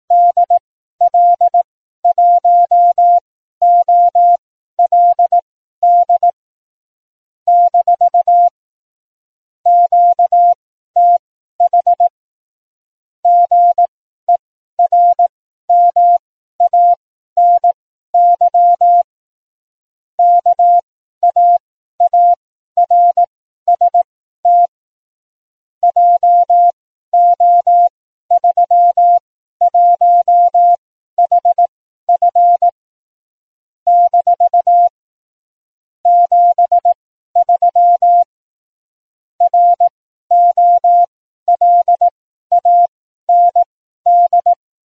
Information in CW-Audio hier: